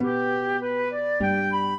flute-harp
minuet12-4.wav